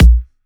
VFH1 128BPM Flatbeat Kick.wav